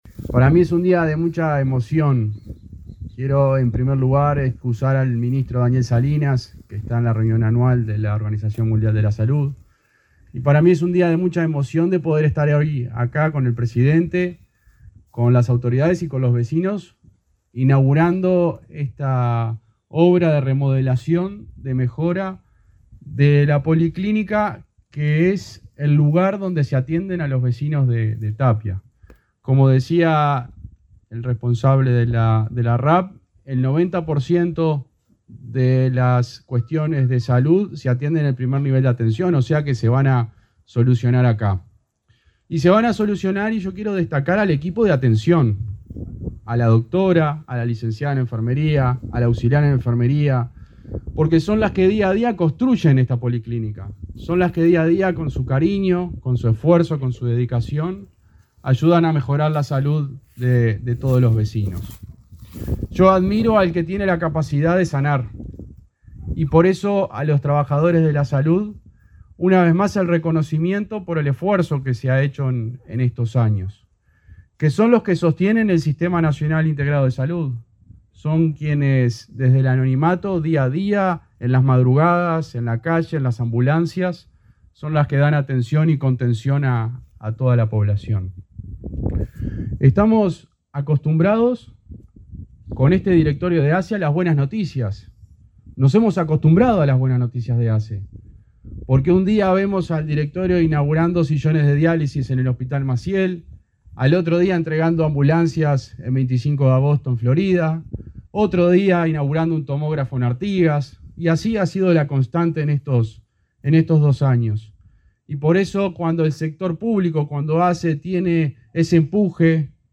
Palabras de autoridades en inauguración de obras de remodelación de policlínica de Tapia, Canelones
El ministro interino de Salud Pública, José Luis Satdjian, y el presidente de ASSE, Leonardo Cipriani, señalaron la importancia de las obras de